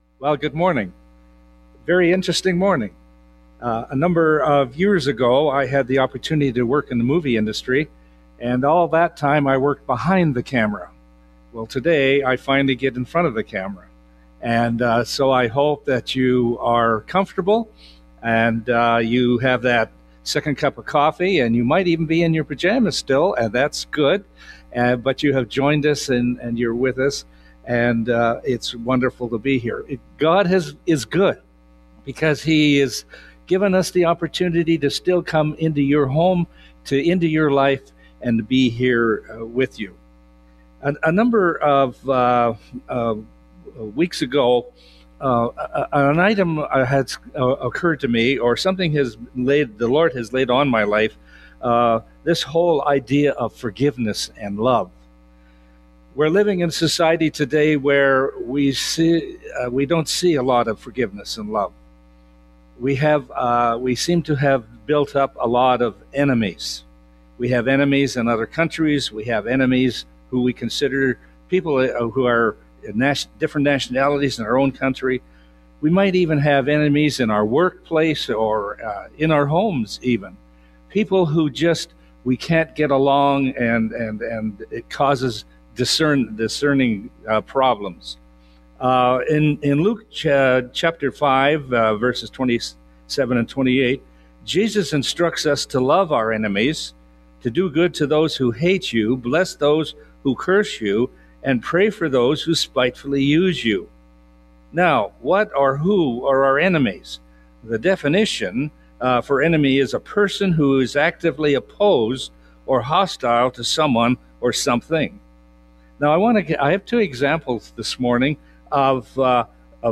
Sermons | Bethel Church Ladysmith